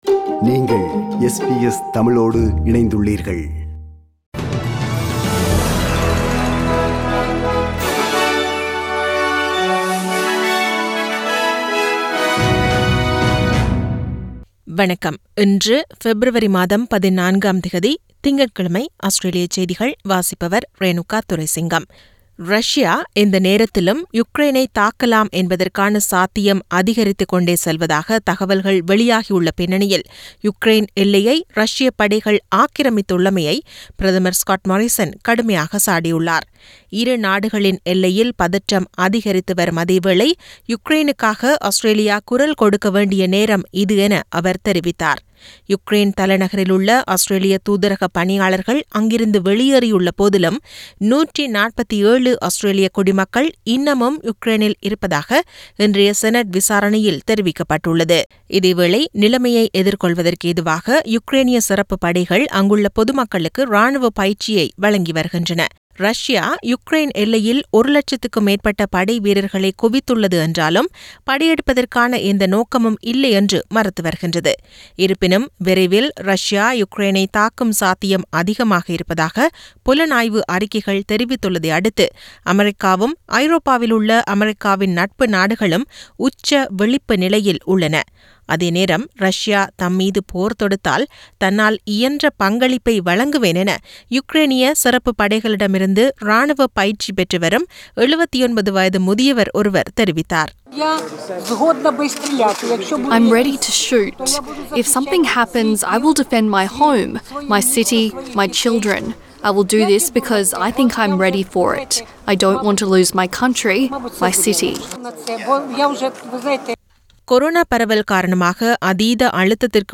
SBS தமிழ் ஒலிபரப்பின் இன்றைய (திங்கட்கிழமை 14/02/2022) ஆஸ்திரேலியா குறித்த செய்திகள்.